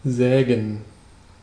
Ääntäminen
IPA: /ˈzɛːɡən/